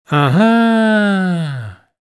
Male Voice ‘Uh-huh’ Sound Effect – Affirming Understanding
Add realism to your projects with this male “Uh-huh” sound effect, clearly showing understanding or agreement.
Genres: Sound Effects
Male-voice-uh-huh-sound-effect.mp3